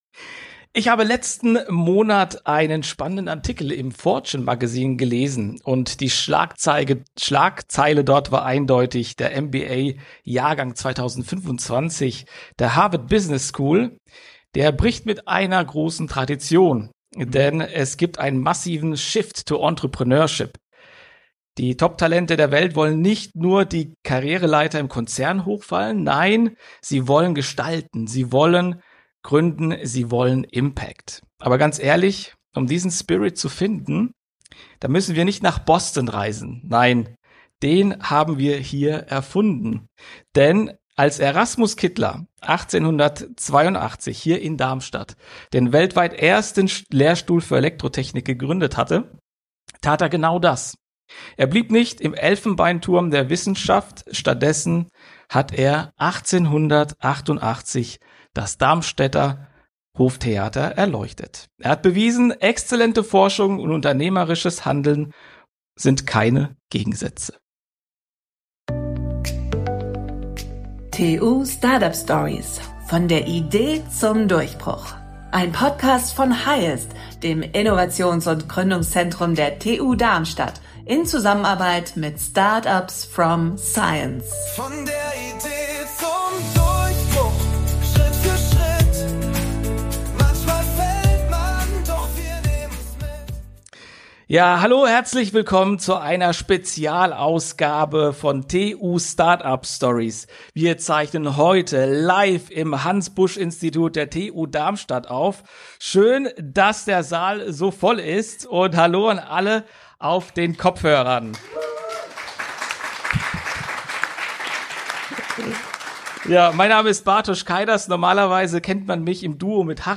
Beschreibung vor 1 Woche Labor, Konzern oder eigenes Startup? In dieser exklusiven Live-Ausgabe aus dem Hans-Busch-Institut der TU Darmstadt diskutieren wir Karrierewege abseits der Norm.